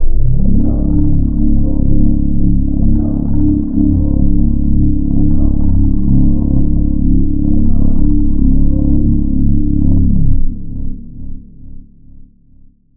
ALIEN的声音飞船关机（SFX）。
描述：我想模仿外星人的未来派怪异声音。
Tag: Sci-网络连接 OWI 怪异 外星人